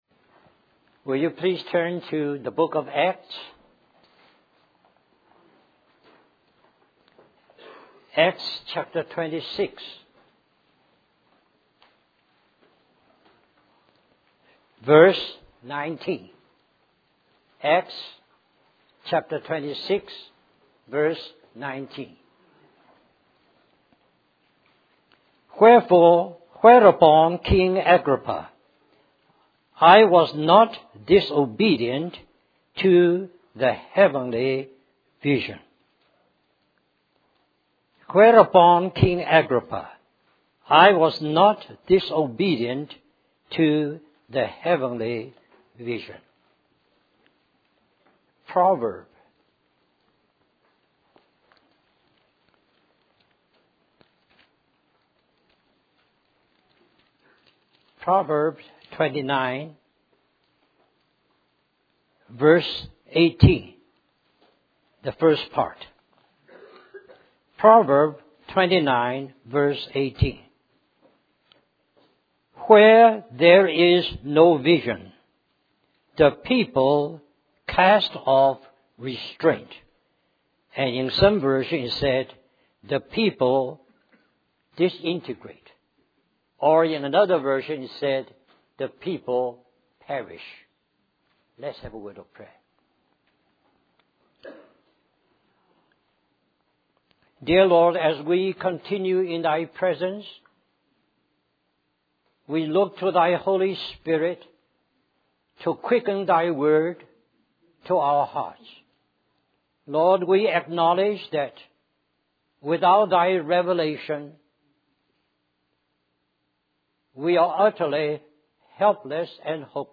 In this sermon, the preacher begins by referencing Genesis chapter one, where God brought light into the darkness of the world. He then connects this concept of light revealing things to the story of a young man who experienced a heavenly light that showed him the darkness in his own life.